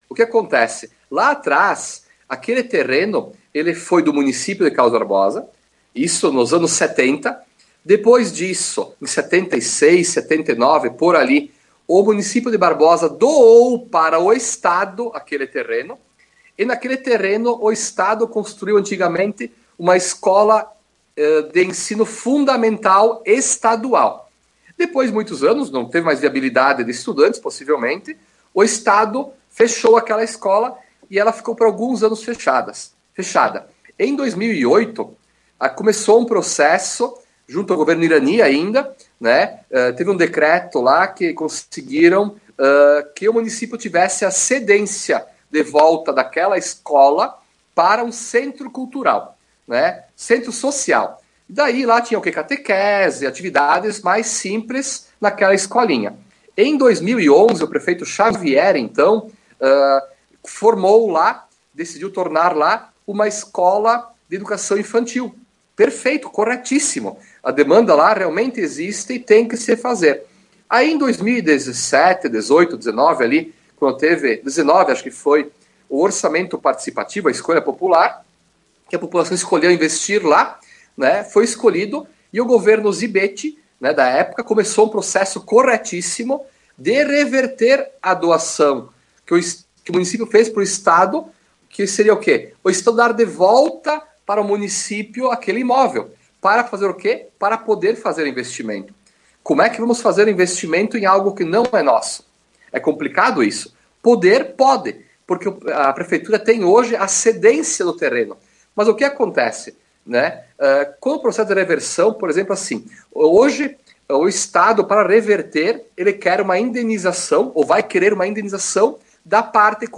O prefeito de Carlos Barbosa, Éverson Kirch, participou de entrevista no Debate, na manhã desta quinta-feira, dia 15/07, para apresentar um balanço dos 180 dias de sua administração.
(Participação do prefeito em escute a notícia)